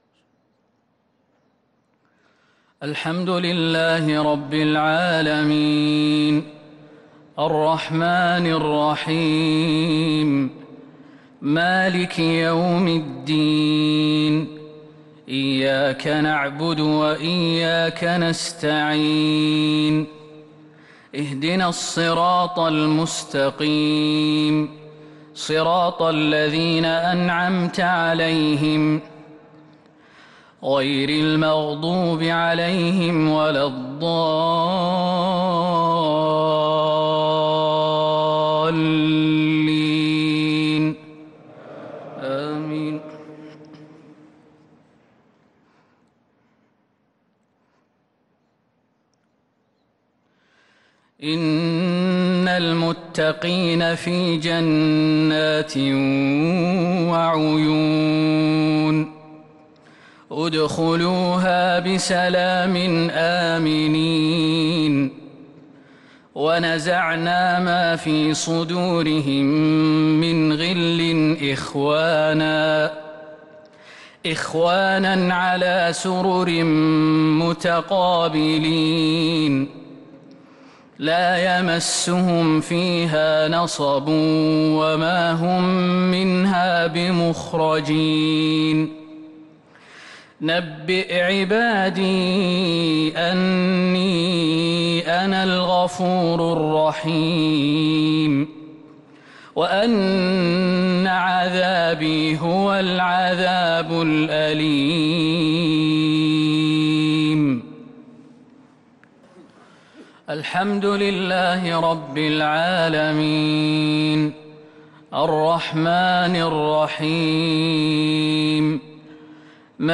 صلاة المغرب للقارئ خالد المهنا 1 ربيع الأول 1443 هـ
تِلَاوَات الْحَرَمَيْن .